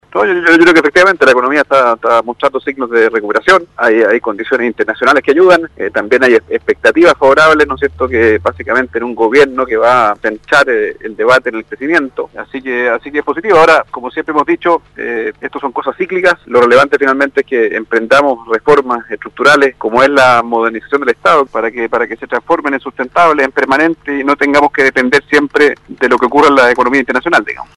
BERNARDO LARRAIN MATTE, presidente de SOFOFA, en conversación con el programa “Haciendo Ciudad” y ante la consulta por el 4,9 de IMACEC en el mes de mayo reconoció que la economía da signos de recuperación ayudada por elementos externos, a la vez estima que ha llegado el momento de abordar la modernización del estado.